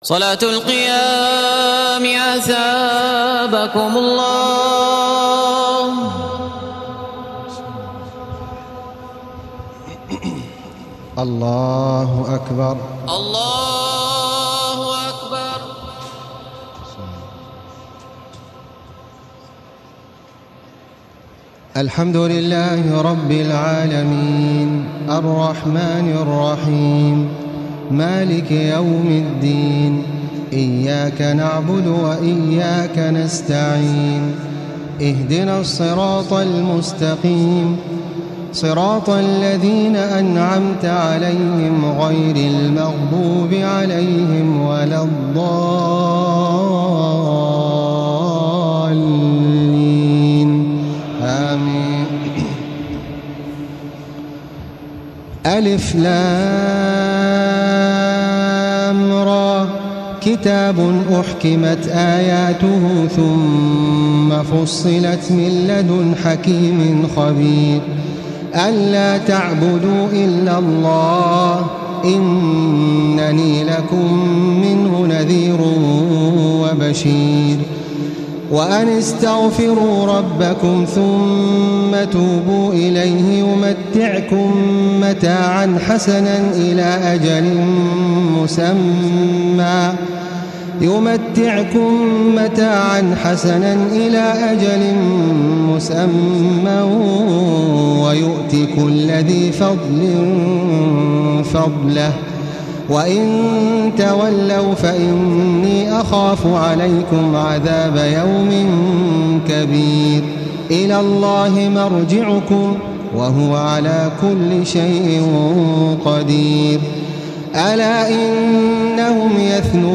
تراويح الليلة الثانية عشر رمضان 1435هـ من سورة هود (1-83) Taraweeh 12 st night Ramadan 1435H from Surah Hud > تراويح الحرم المكي عام 1435 🕋 > التراويح - تلاوات الحرمين